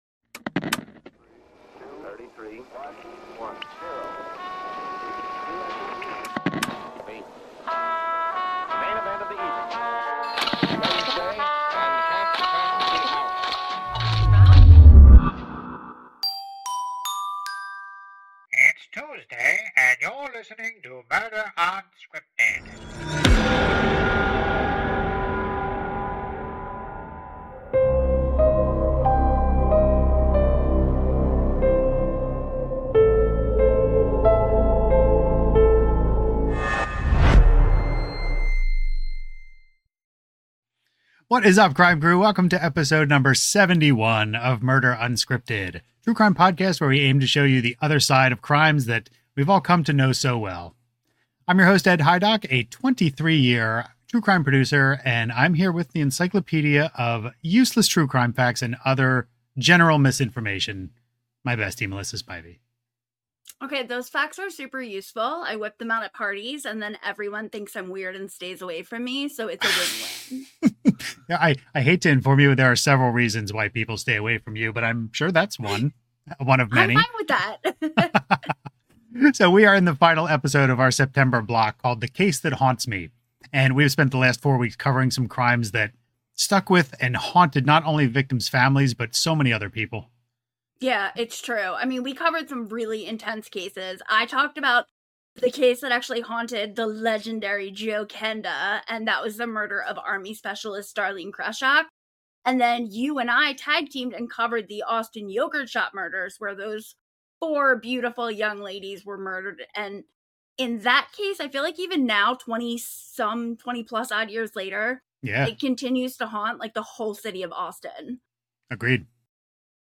Our unique dynamic resonates with both seasoned true-crime fans and newbies alike, while our unscripted interviews with detectives, forensic scientists, prosecutors, and victims’ families bring you far beyond recycled headlines - immersing you in the world of true-crime like no one else.